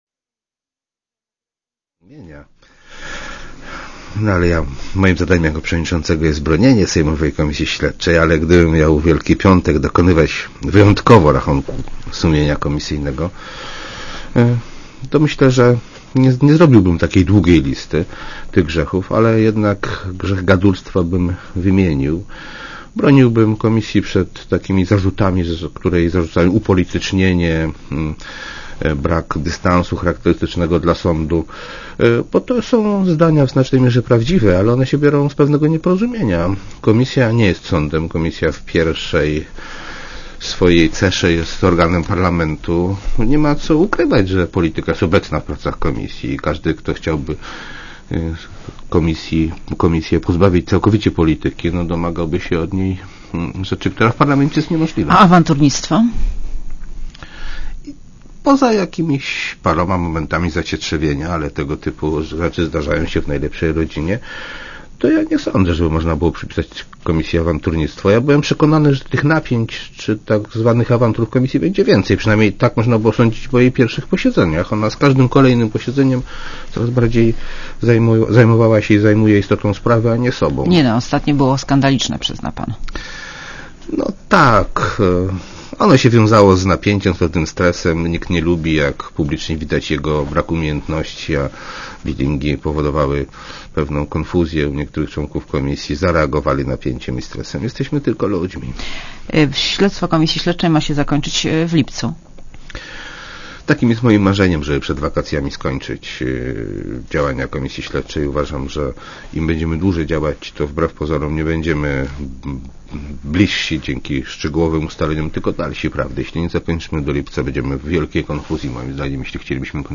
RadioZet) Posłuchaj wywiadu (2,7 MB) Panie Marszałku, jakie są grzechy sejmowej komisji śledczej?